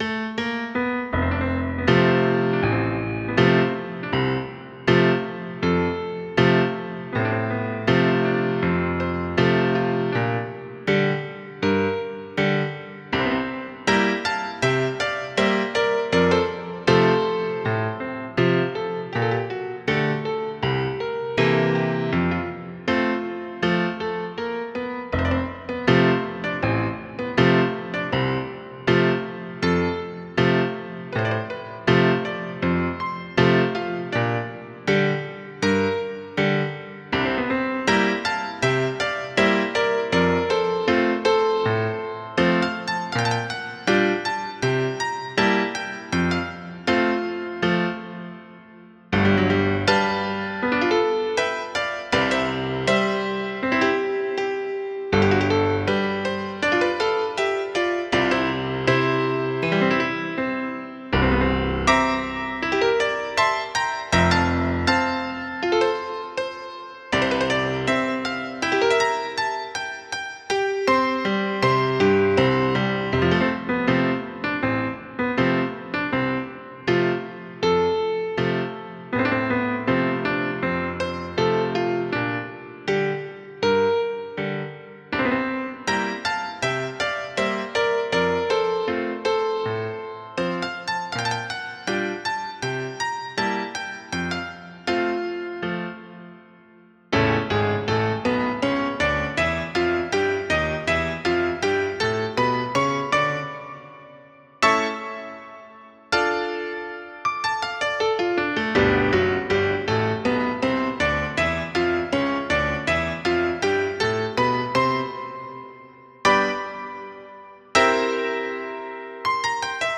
piano
danza
romanticismo